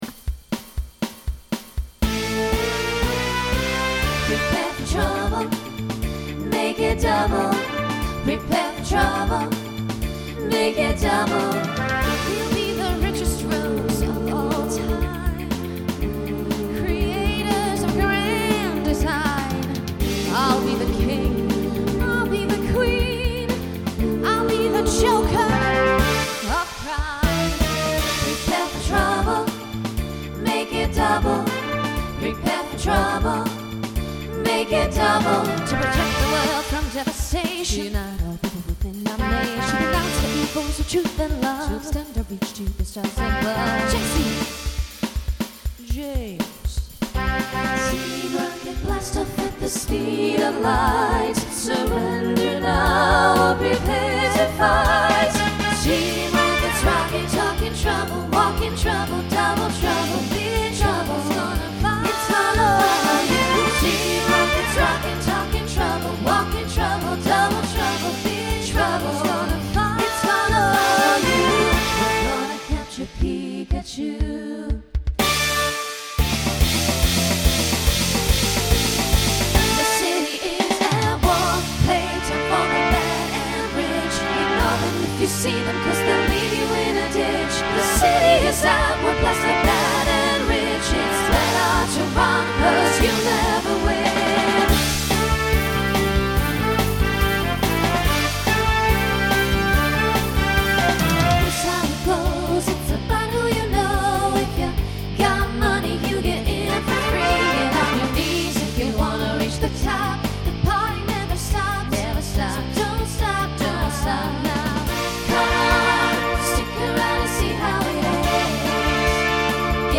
Voicing SATB Instrumental combo Genre Rock
Mid-tempo